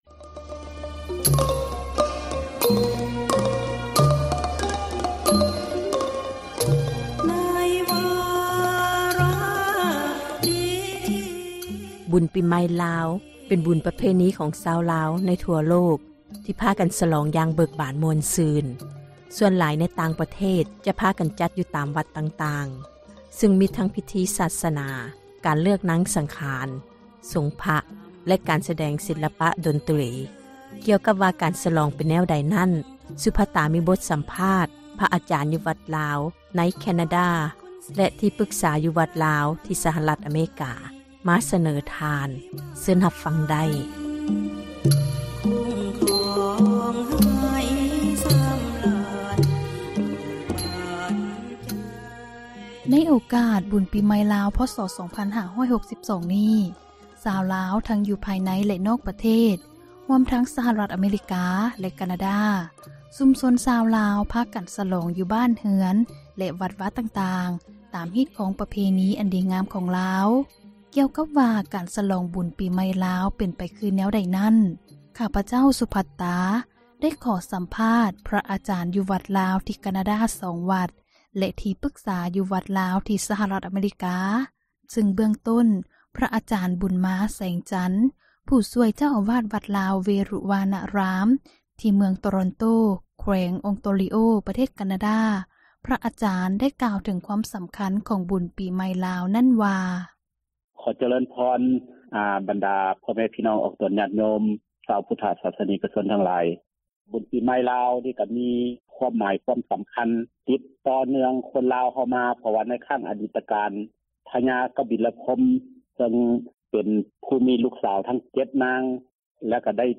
ທີ່ທ່ານຜ່ານໄດ້ຮັບຜ່ານນັ້ນ ແມ່ນການສໍາພາດກ່ຽວກັບການສເລີມສລອງບຸນປີໃໝ່ລາວ ພ.ສ 2562 ທີ່ປະເທດການາ ແລະ ສະຫະຣັຖອາເມຣິກາ